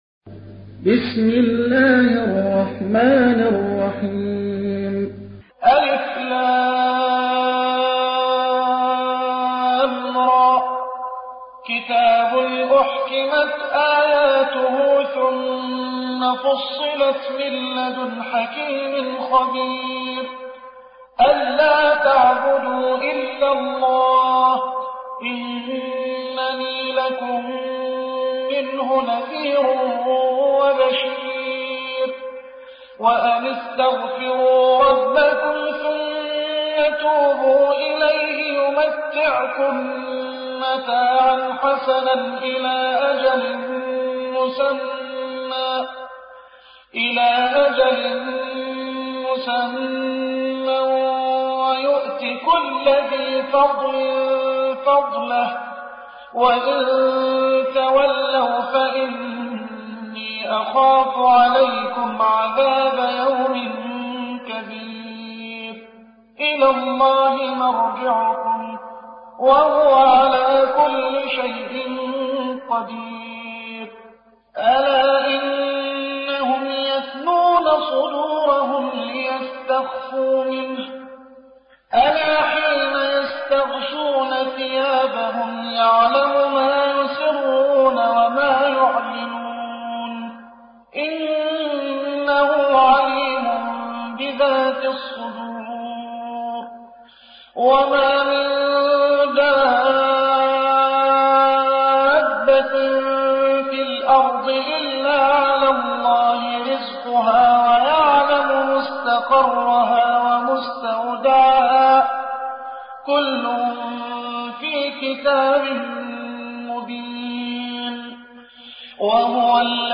11. سورة هود / القارئ